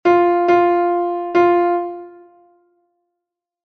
O síncope é unha figura que se prolonga da parte débil á forte do compás, provocando un cambio de acento.
corchea, corchea ligada a corchea e corchea é igual a corchea, negra e corchea; 4 semicorcheas ligadas as do medio é igual a semicorchea, corchea e semicorchea